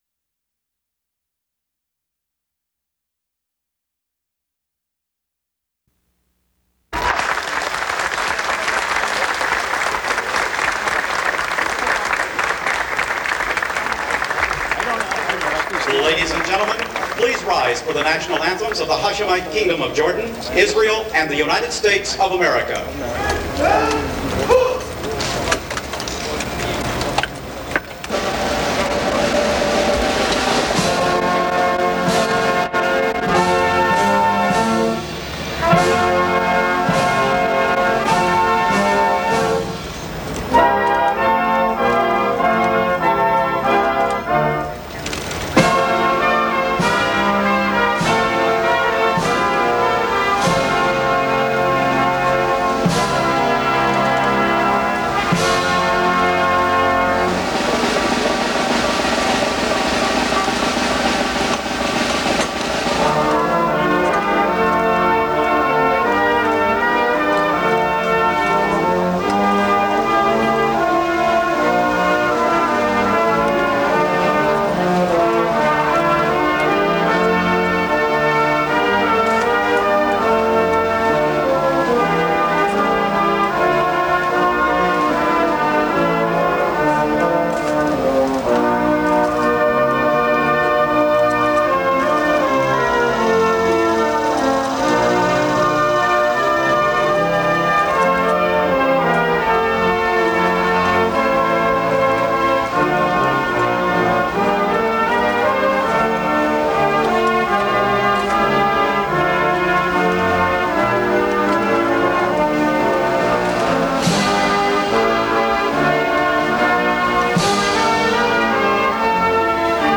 U.S. President Bill Clinton, King Hussein of Jordan, and Yitzhak Rabin of Israel speak before signing the historic peace treaty